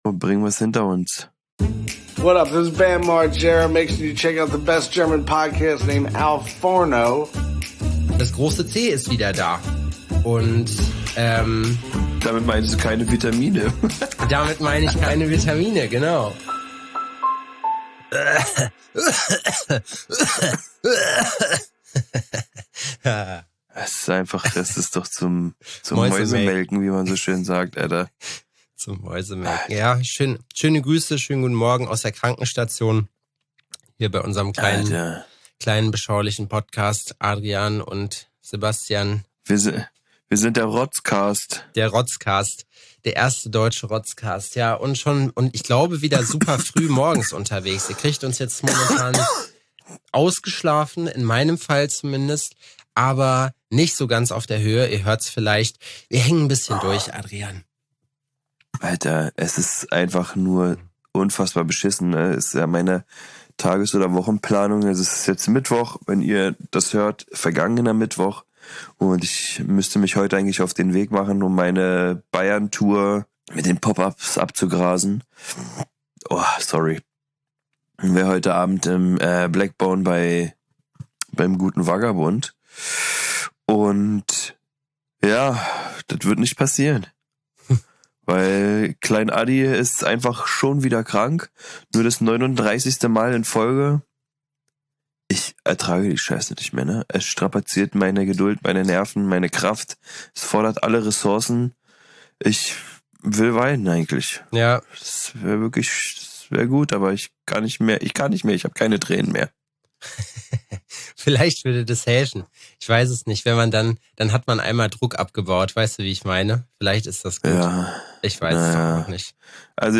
Dennoch soll der wöchentliche Plausch nicht zu kurz kommen, denn zu erzählen gibt es viel. Lass dir behutsam feucht ins Ohr Husten!